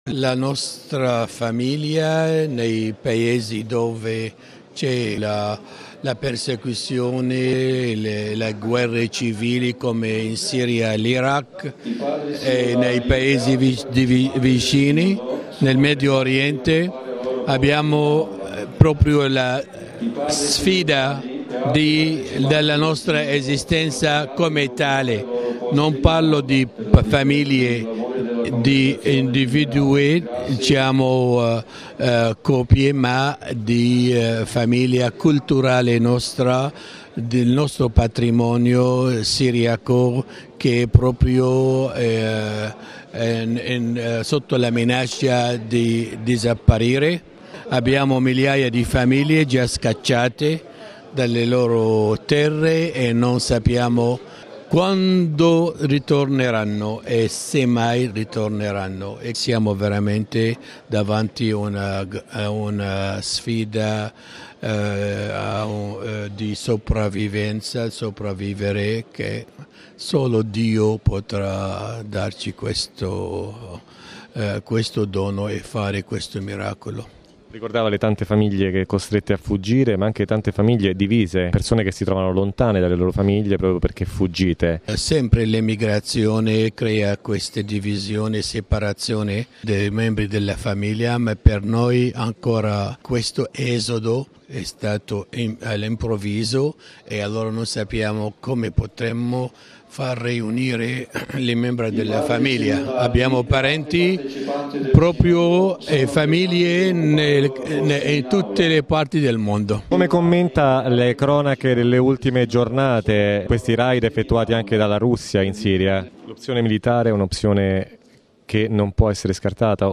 Al Sinodo si sta parlando del dramma di tante famiglie cristiane che hanno dovuto lasciare Siria e Iraq per la guerra e le persecuzioni del sedicente Stato Islamico. A lanciare il grido delle famiglie cristiane del Medio Oriente, spesso divise dalle violenze, è stato, tra gli altri, il patriarca siro-cattolico Ignace Youssif III Younan.